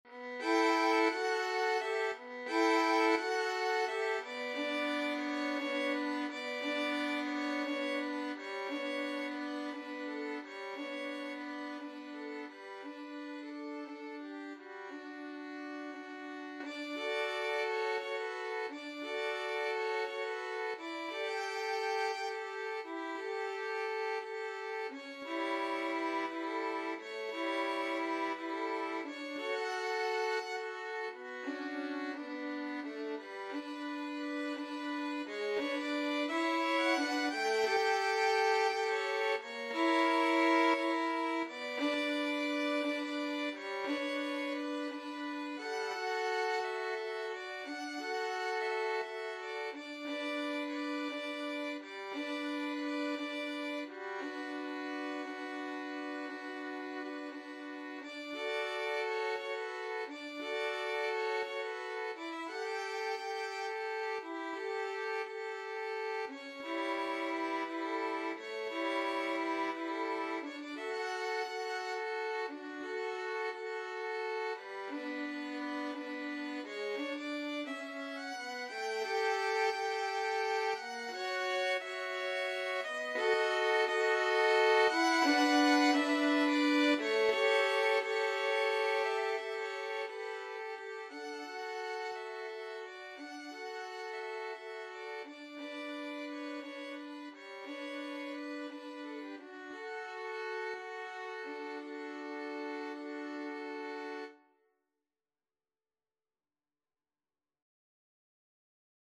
6/8 (View more 6/8 Music)
.=58 Andante con moto (View more music marked Andante con moto)
B minor (Sounding Pitch) (View more B minor Music for Violin Duet )
Violin Duet  (View more Intermediate Violin Duet Music)
Classical (View more Classical Violin Duet Music)